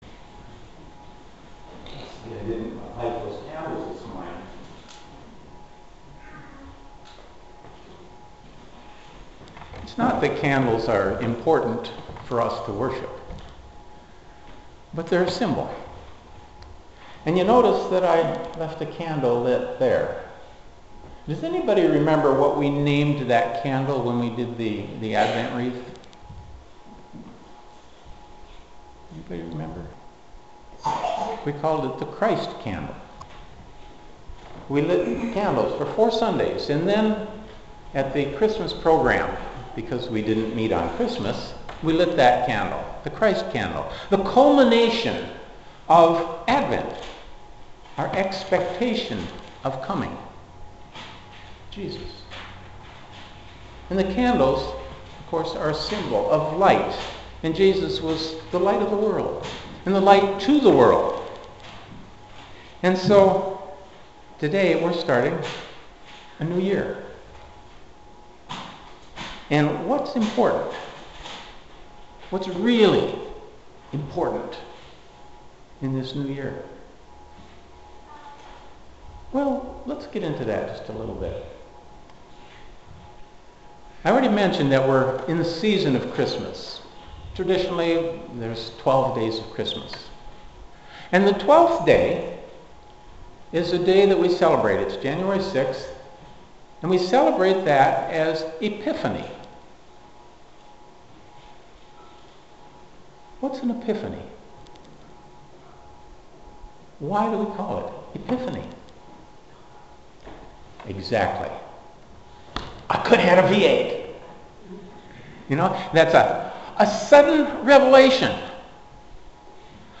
Audio recordings of sermons presented to the Winnnebago Reformed Church
Sermon Recordings